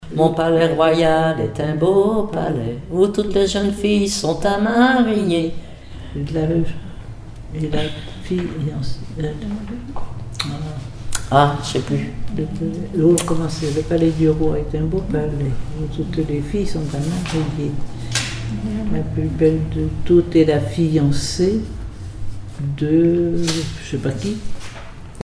rondes enfantines (autres)
comptines et formulettes enfantines
Pièce musicale inédite